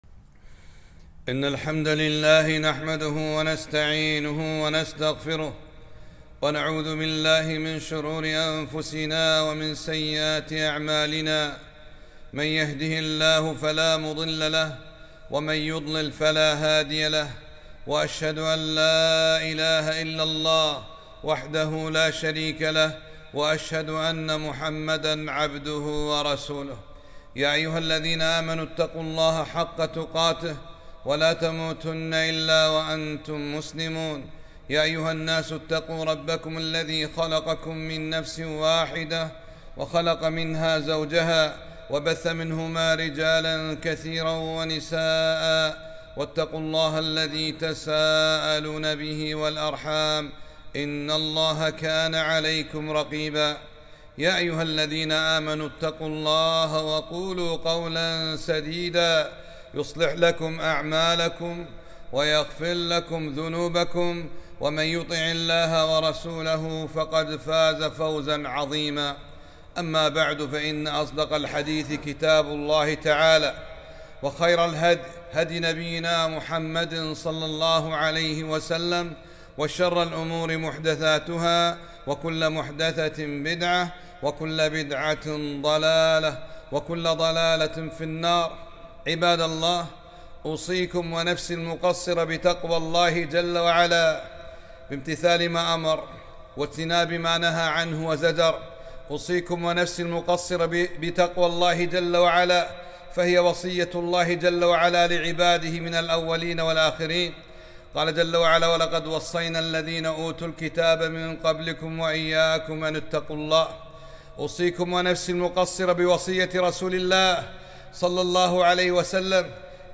خطبة - تحذير المسلمين من الأستهزاء بالدين